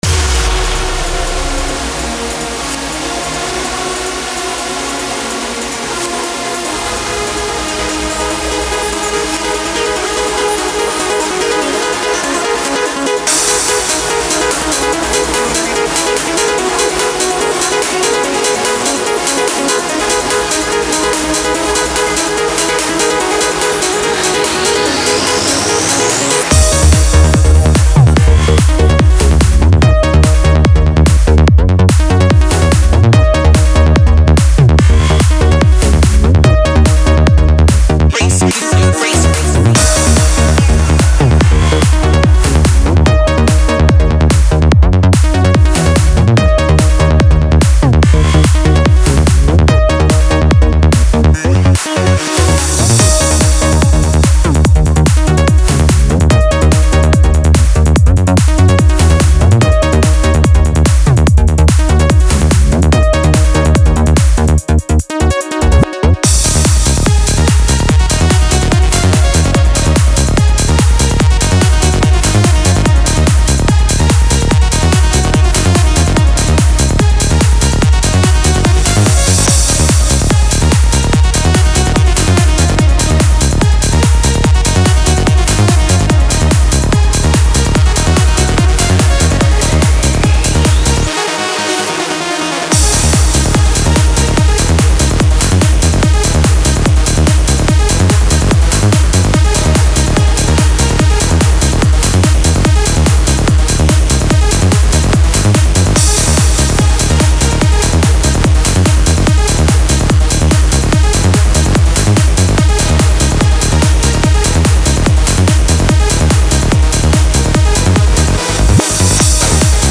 Trance
Транс музыка